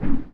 WHOOSH_Deep_Smooth_01_mono.wav